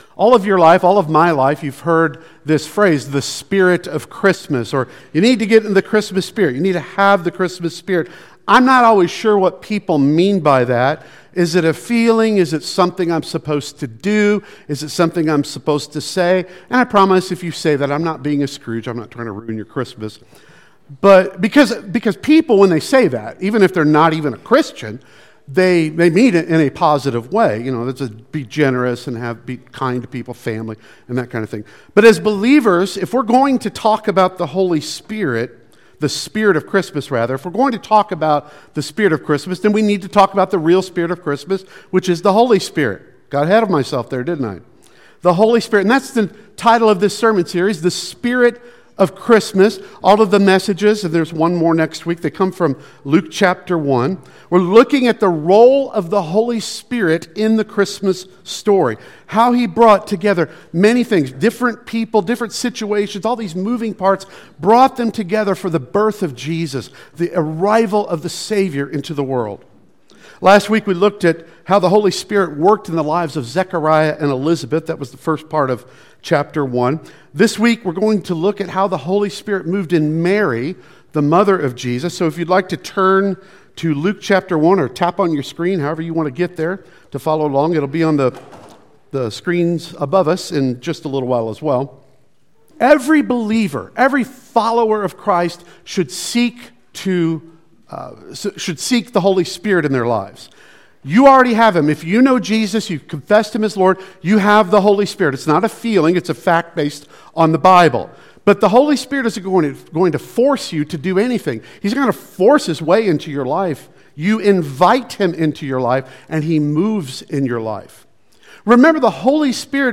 Service Type: Sunday Worship Service